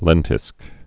(lĕntĭsk)